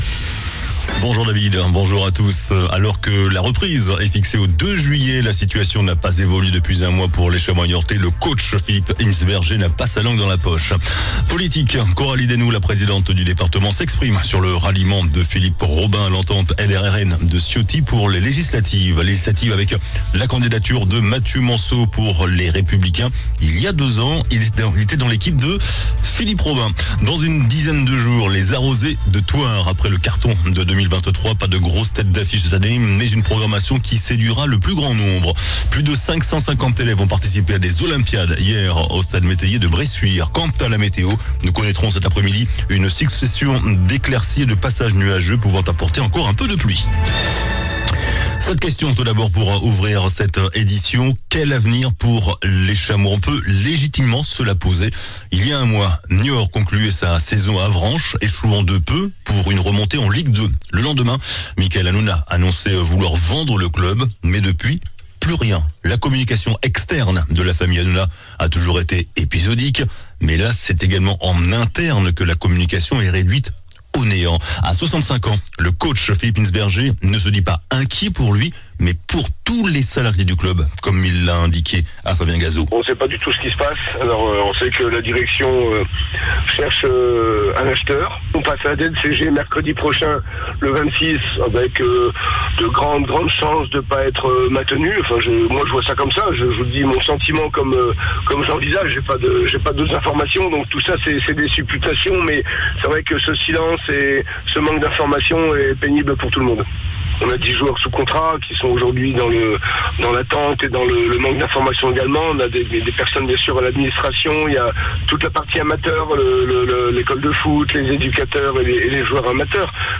JOURNAL DU VENDREDI 21 JUIN ( MIDI )